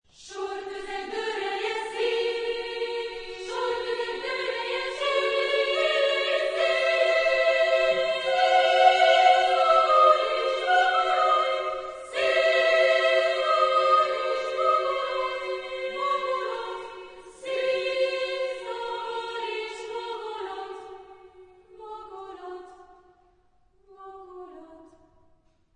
Genre-Style-Form: Madrigal ; Secular
Type of Choir: SSSSA  (5 women voices )
Tonality: free tonality